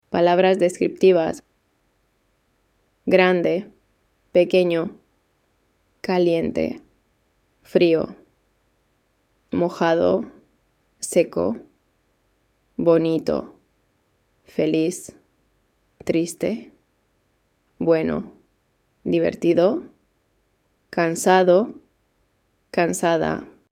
Lesson 7